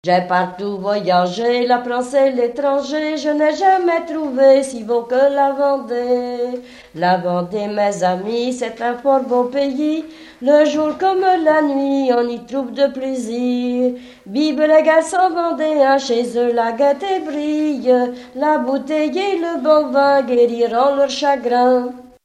danse
Pièce musicale inédite